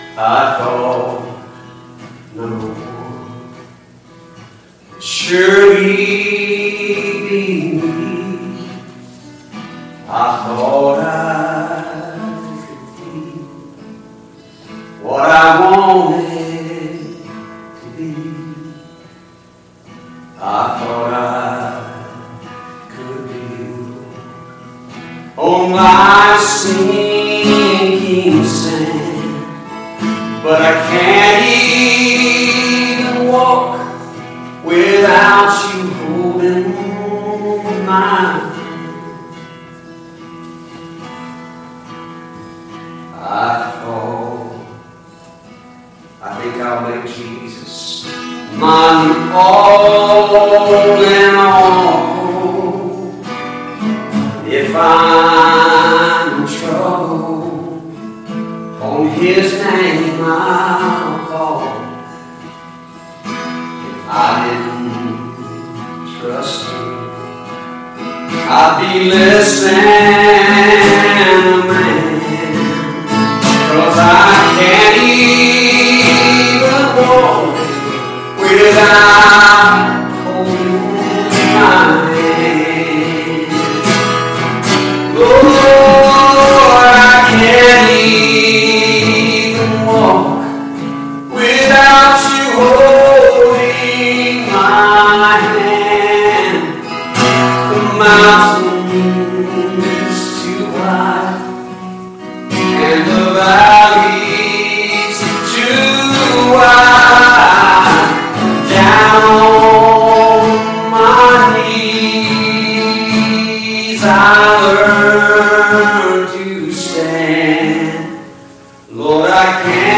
Bethel Church Service
Special Music